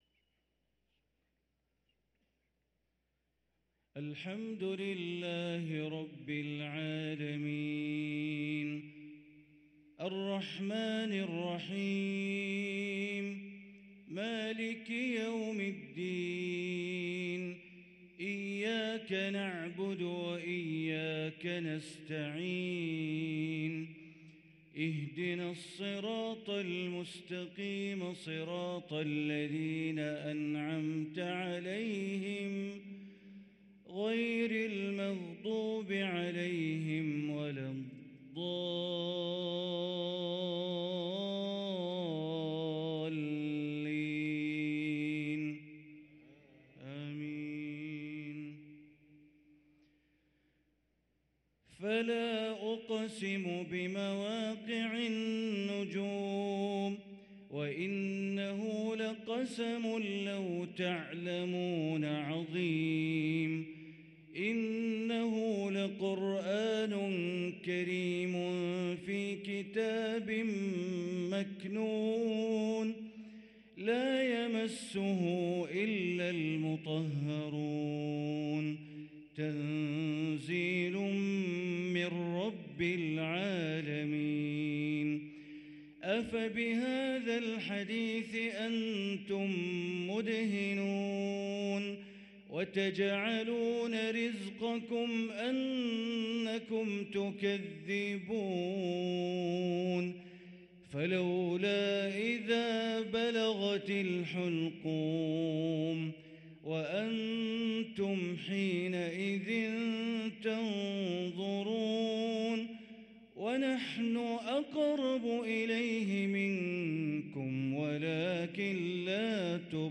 صلاة العشاء للقارئ بندر بليلة 27 جمادي الآخر 1444 هـ